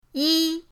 yi1.mp3